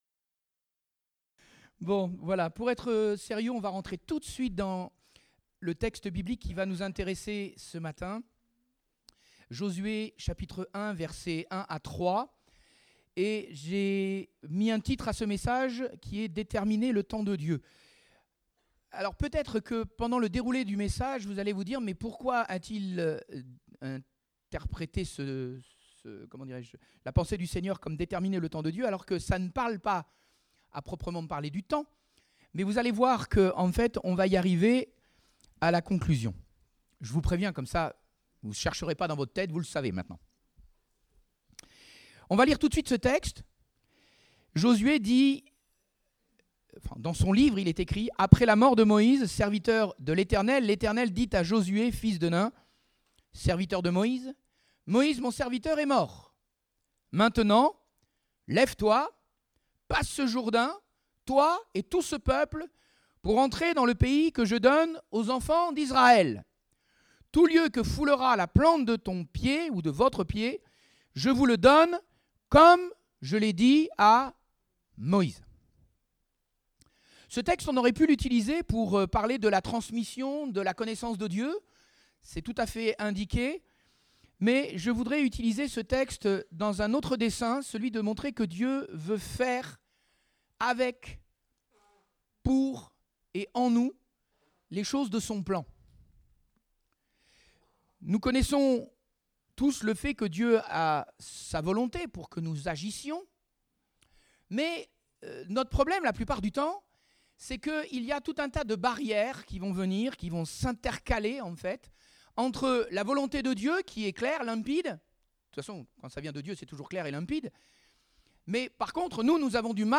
Date : 18 février 2018 (Culte Dominical)